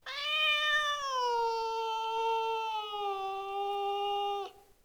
meow2.wav